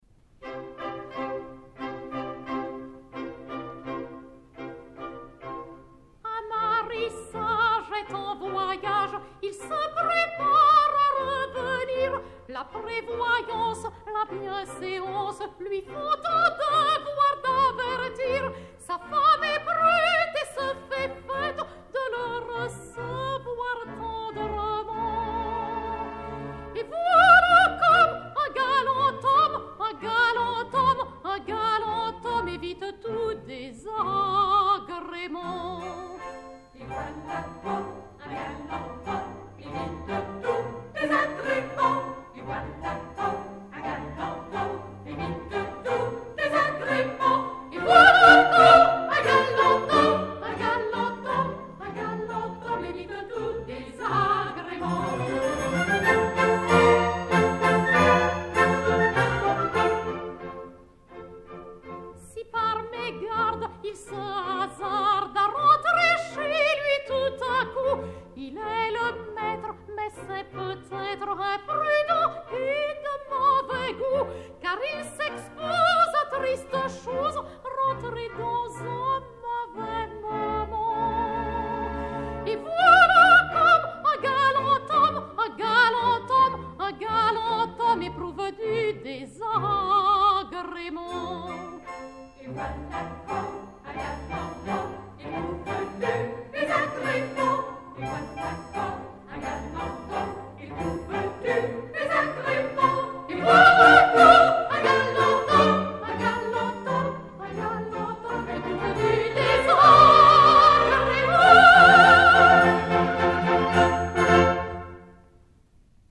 45 tours Orphée, enr. en 1964